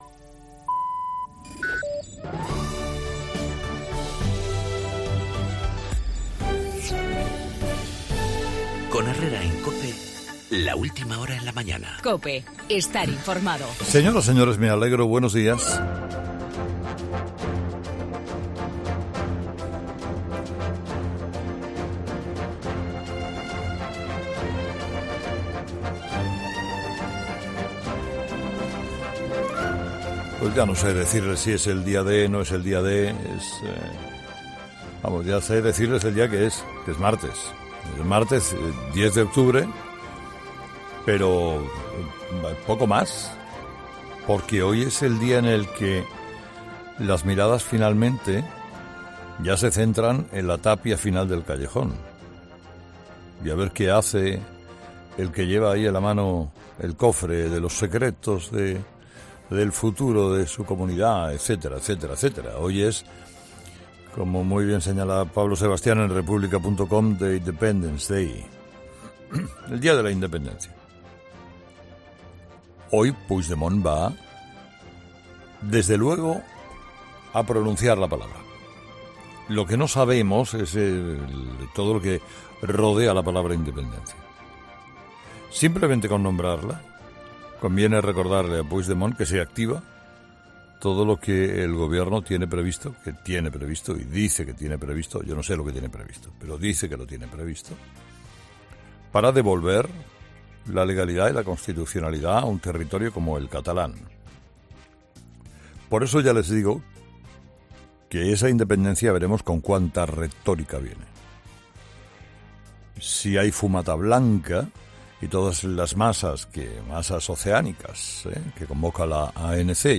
Monólogo de las 8 de Herrera
El futuro de la Generalidad si Puigdemont proclama la independencia, en el editorial de Carlos Herrera